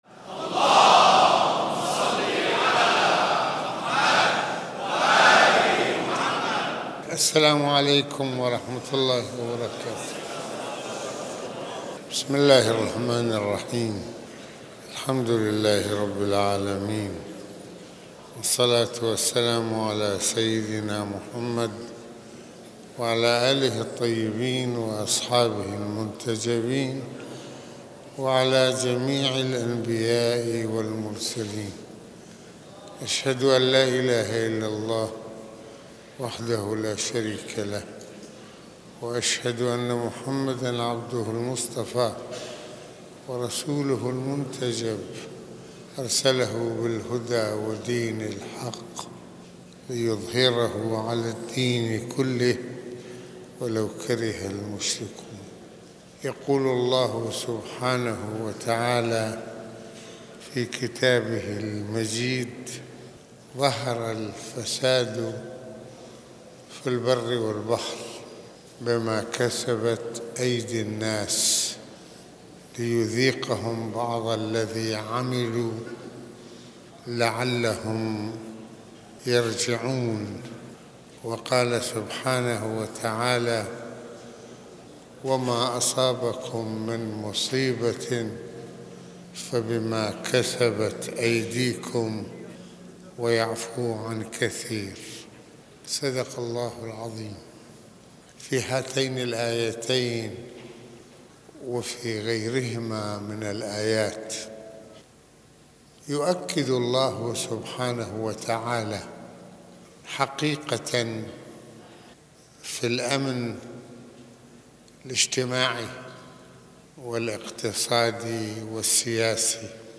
- المناسبة : خطبة الجمعة المكان : مسجد الامامين الحسنين المدة : 29د | 54ث المواضيع : الفساد في الأرض - الفساد بما كسبت أيدي النّاس - الفساد السياسيّ وليد الفتنة - الفساد الاقتصاديّ والاجتماعيّ - نماذج من (المفسدين في الأرض) - فساد النّاس من فساد العلماء والأمراء.